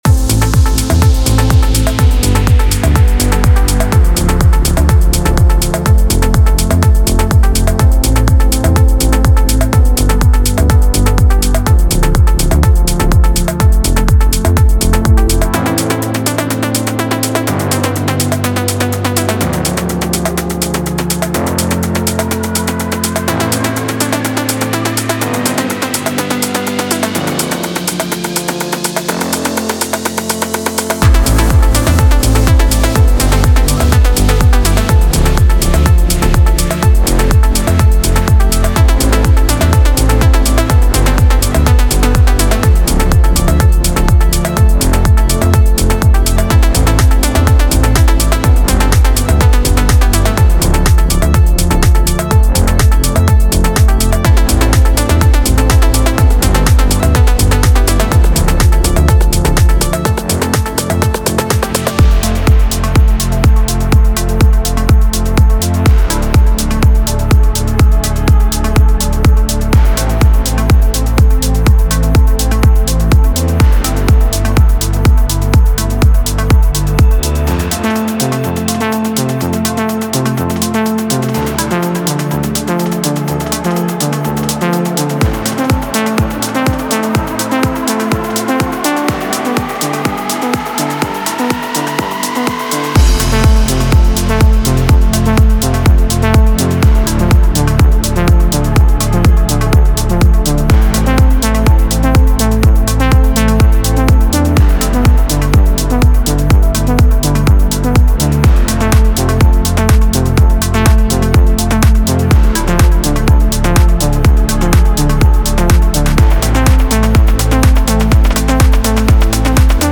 Genre:Deep House
エモーショナルで没入感のあるサウンドでトラックに火をつける
デモサウンドはコチラ↓